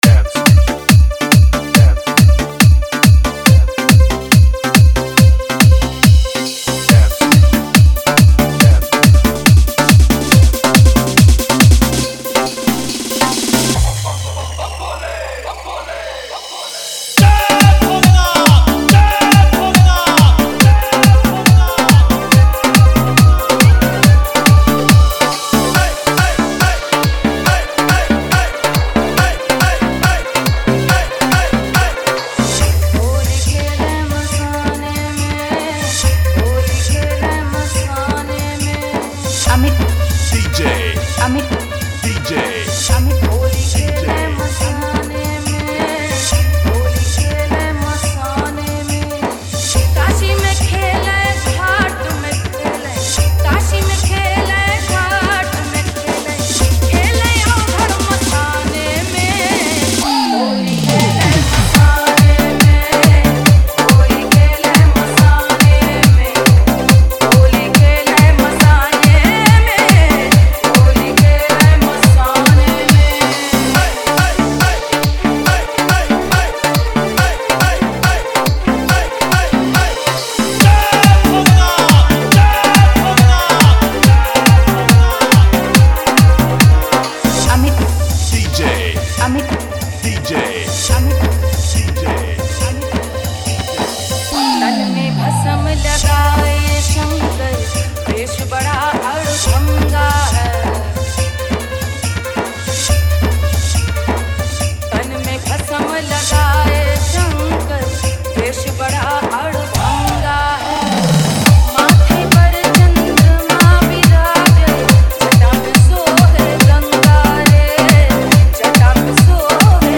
Bhakti holi dj mix
Holi dance dj remix
Holi desi drop dj remix